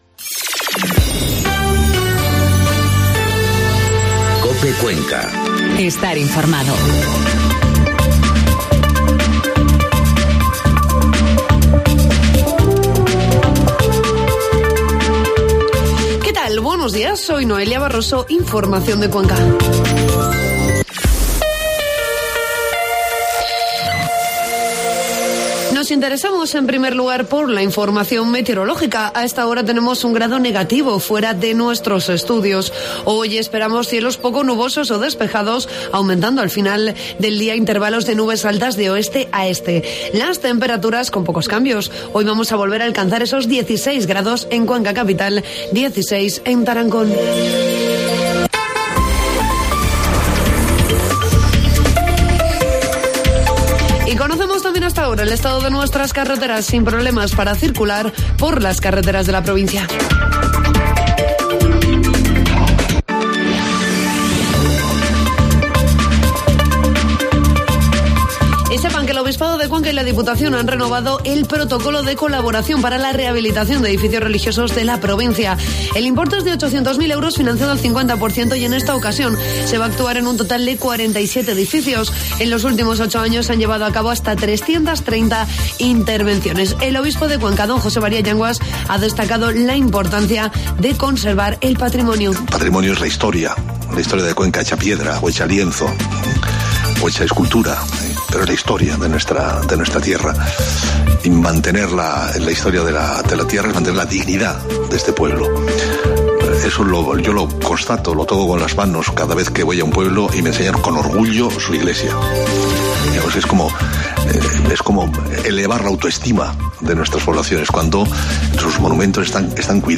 Informativo matinal COPE Cuenca 6 de febrero